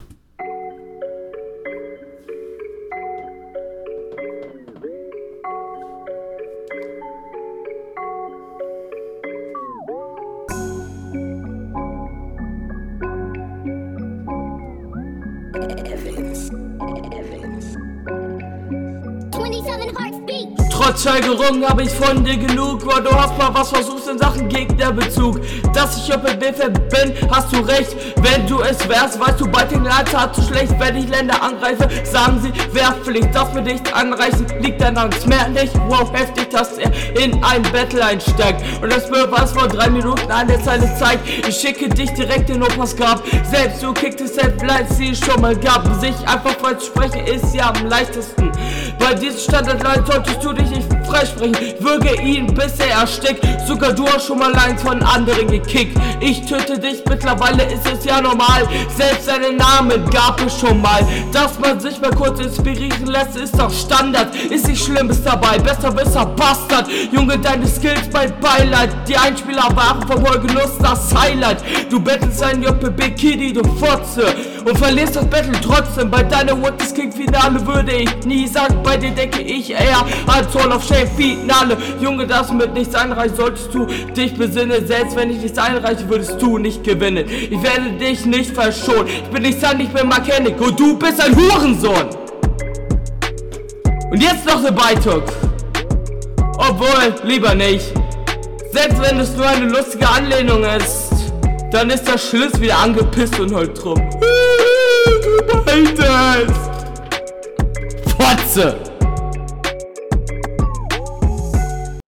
Dein Mic übersteuert durchgehend total, mach vielleicht den gain beim aufnehmen bisschen runter oder geh …